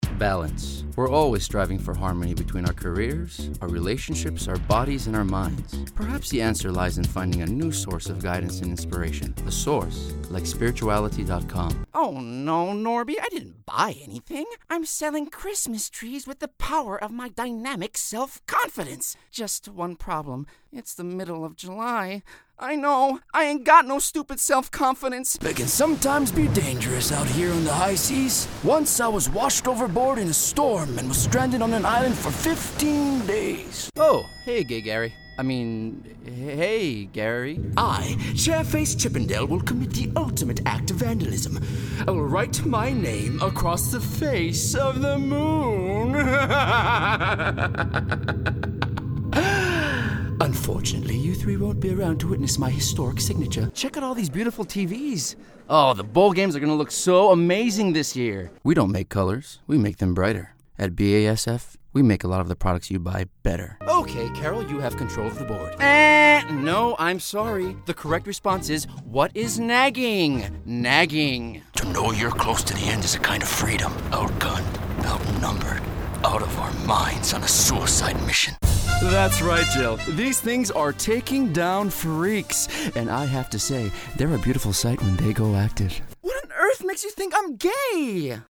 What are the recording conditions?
mo-reel-with-fx.mp3